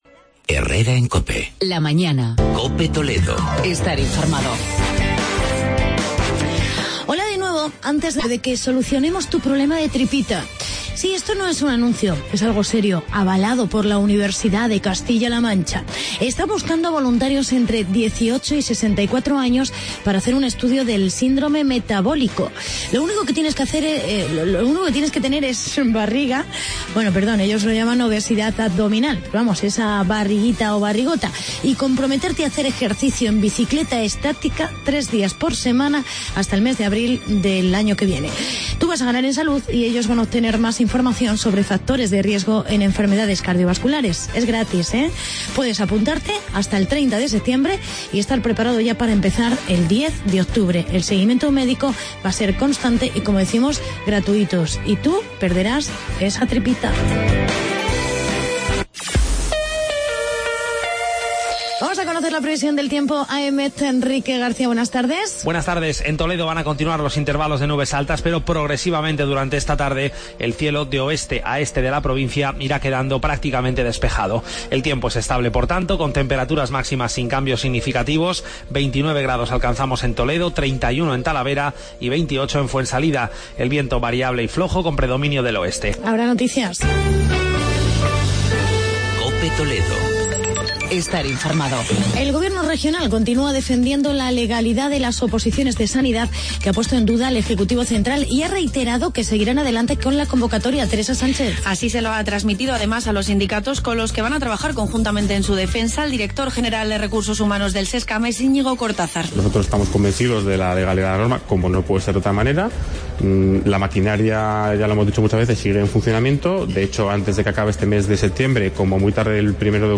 Actualidad y entrevista con el delegado del gobierno, José Julián Gregorio sobre el parking de Santa Teresa.